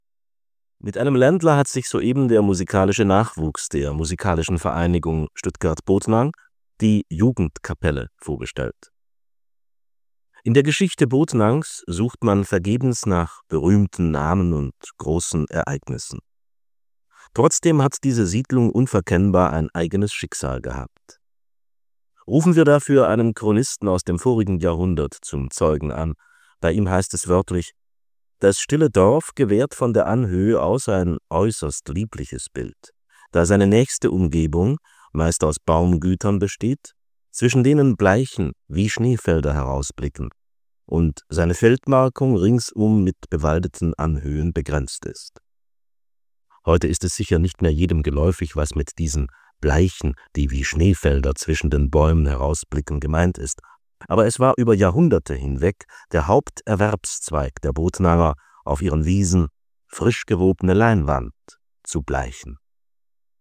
Bei der Ausstrahlung der Sendung am 24.05.1975 saß sie mit ihrem Kassettenrecorder vor dem Radio und nahm die ganze Sendung auf.